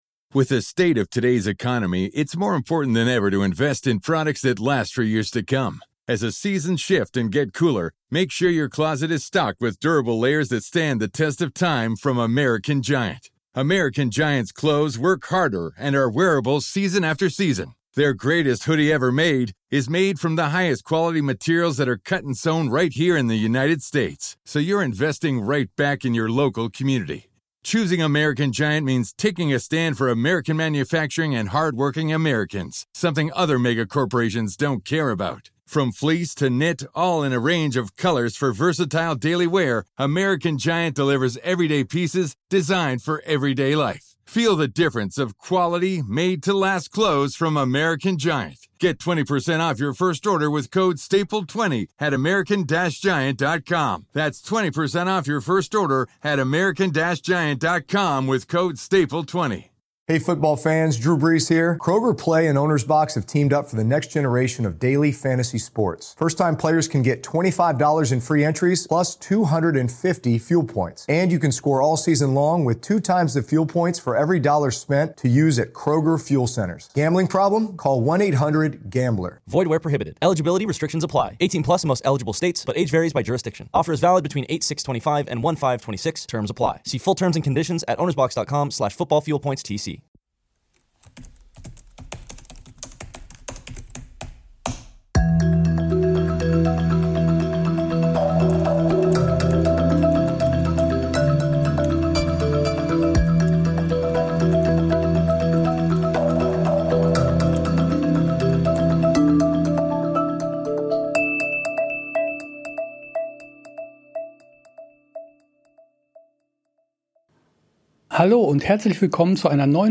Der Landesbeauftragte Michael Panse spricht im Podcast über Israel-Kritik, Antisemitismus, die Stimmung in der jüdischen Landesgemeinde und eine geplante Reise des Ministerpräsidenten.